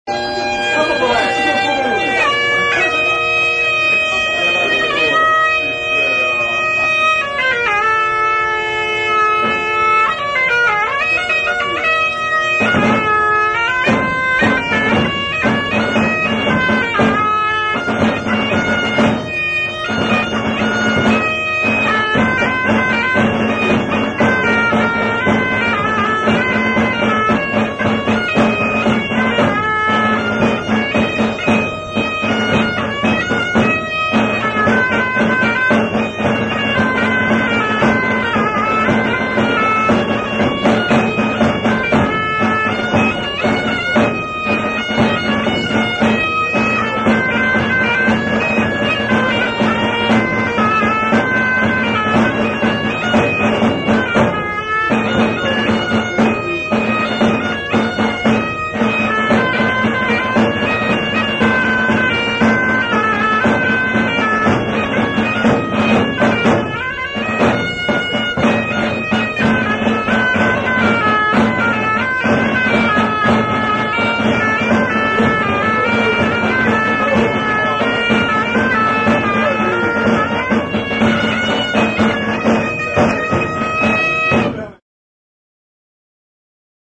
CD111 – RECOLHAS EM TERRAS DE MIRANDA E VIMIOSO